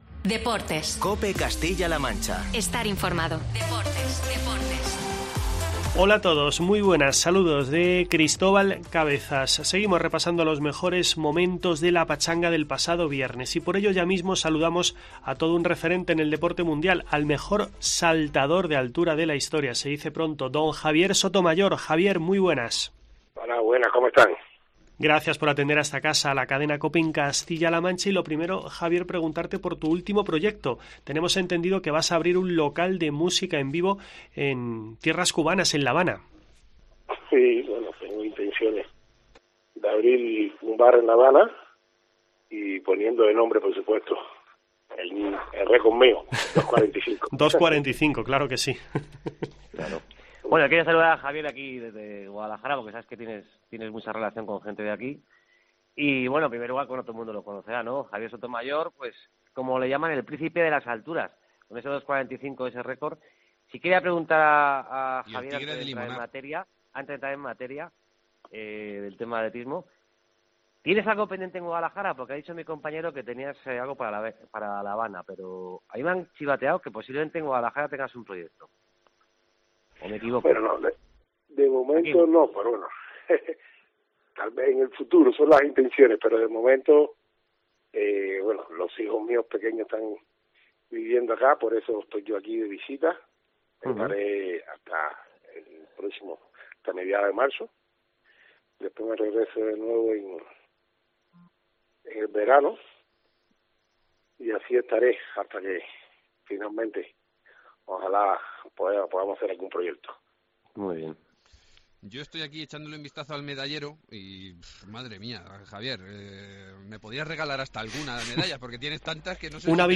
AUDIO: No te pierdas la entrevista con el mejor saltardor de altura de la historia